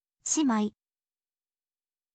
shimai